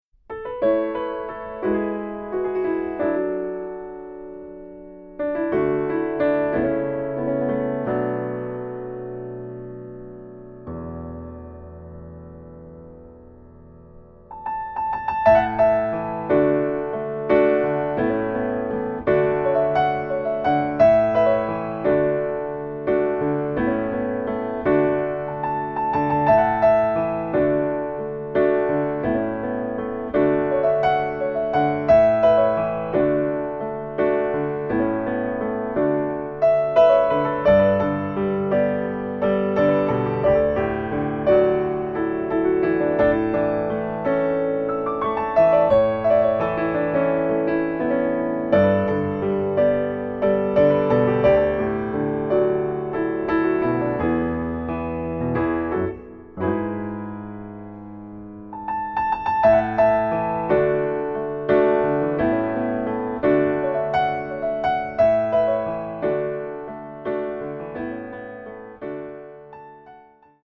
Level : Intermediate | Key : A | Individual PDF : $3.99